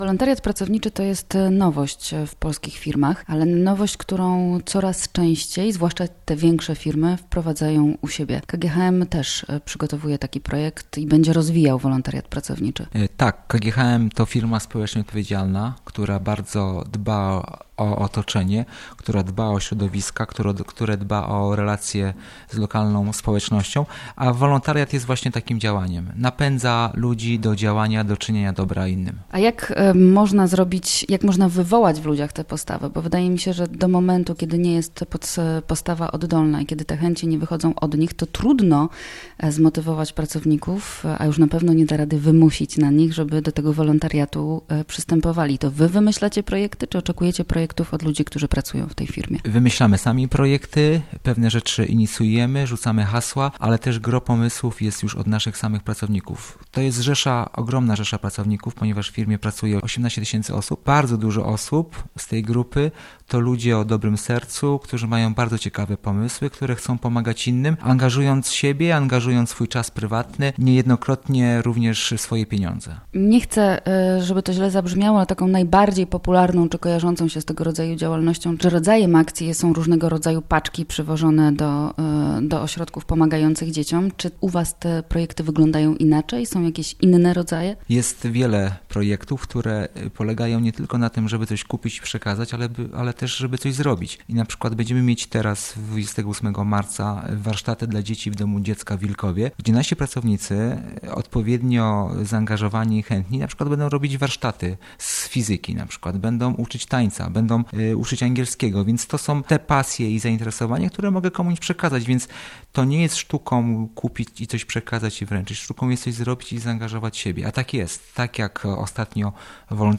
O wolontariacie w KGHM w Radiu Luz opowiadał